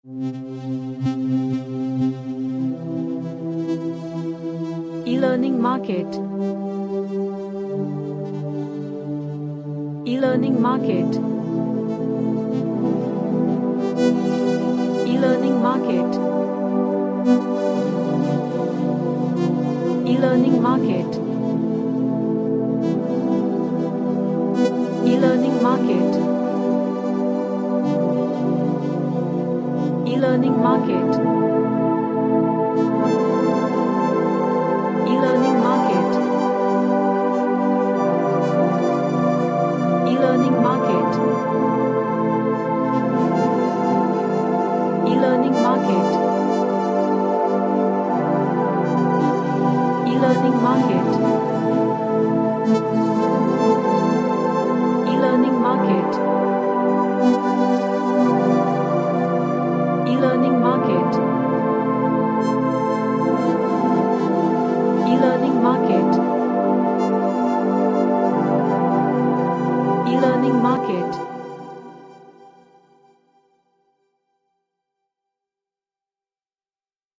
An Ambient track with relaxing vibe.
Relaxation / Meditation